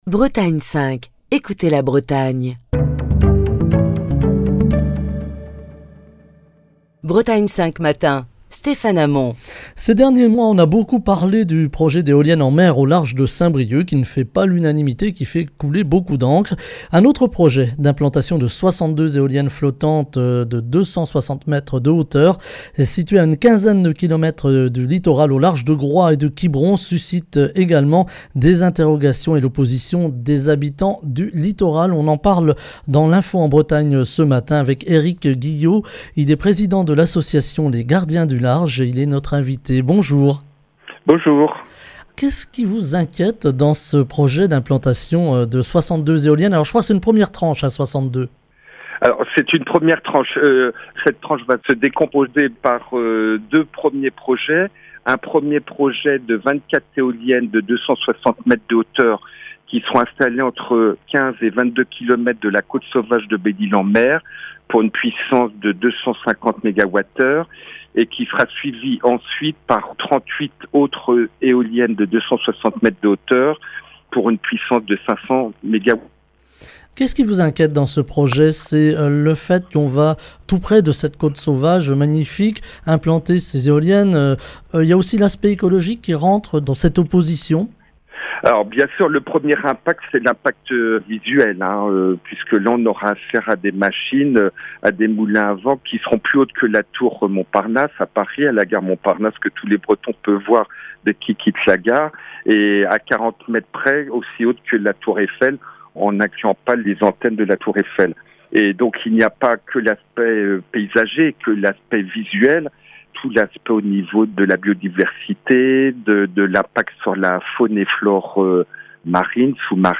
Émission du 15 septembre 2022.